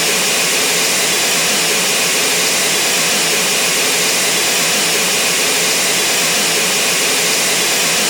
x320_cfm_starter.wav